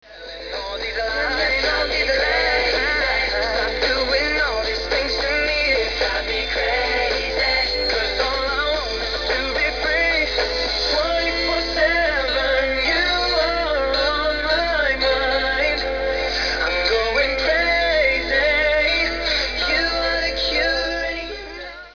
It's a very cool up-tempo song and very new
with a RAP-PART !!